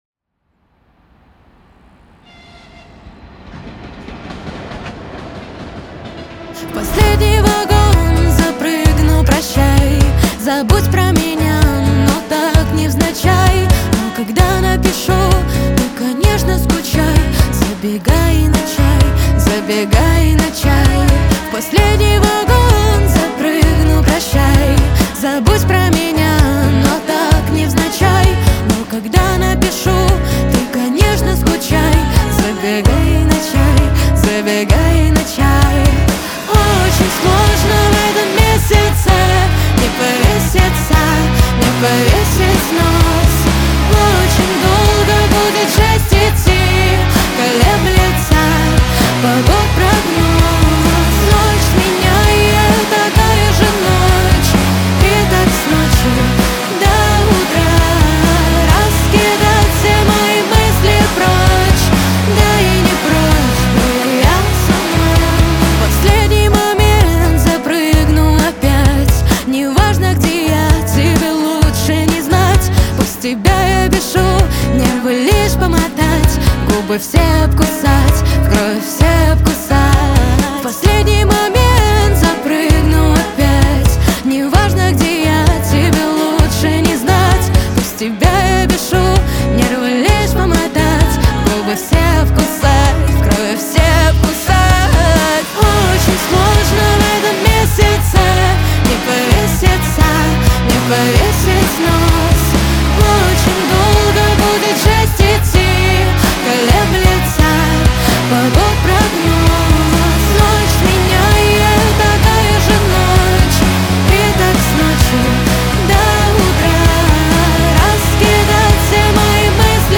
Рок музыка 2025